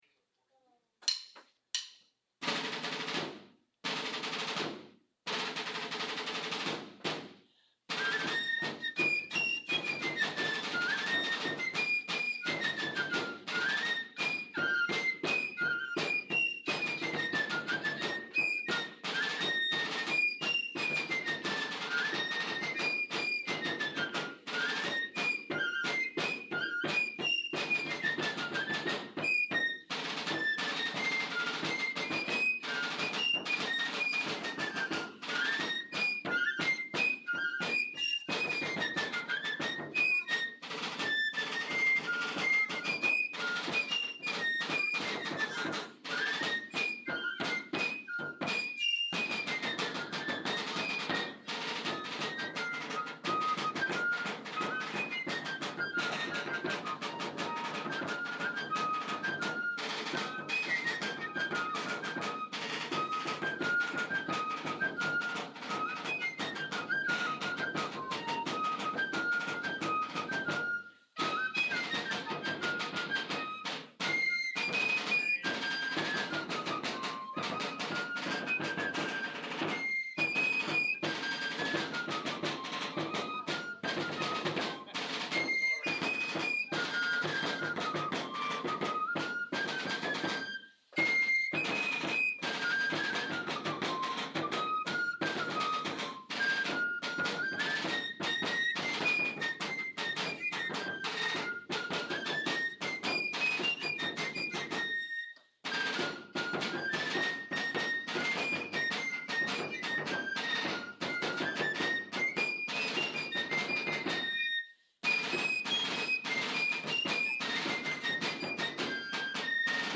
Janesville Fife and Drum Corps | Music
A performance of music from America’s colonial period